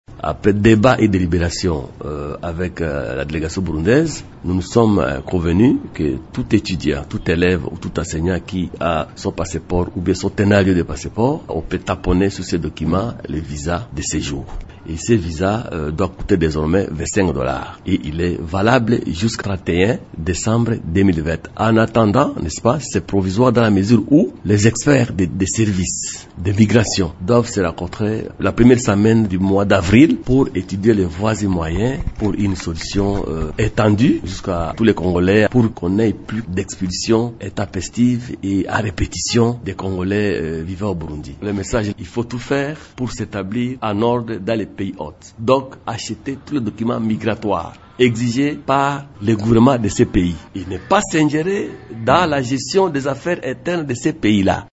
Vous pouvez écouter Raymond Tchedya dans cet extrait sonore :